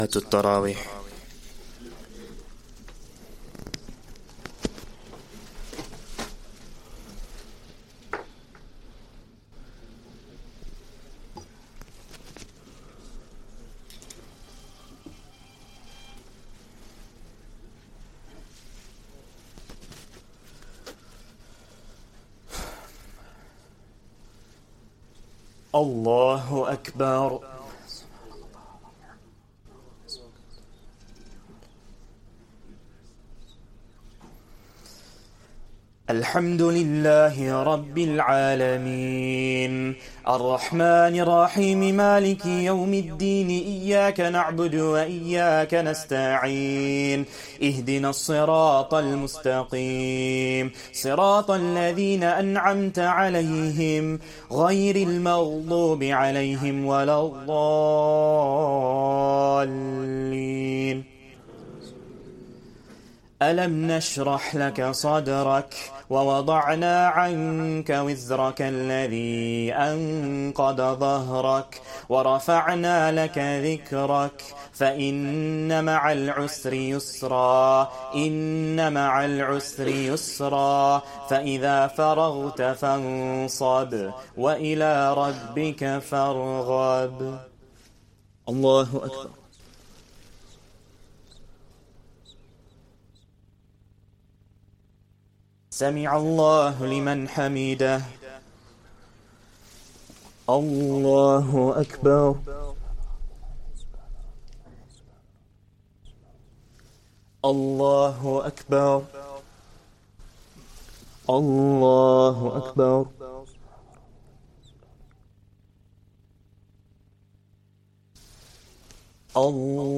Taraweeh Prayer 27th Ramadan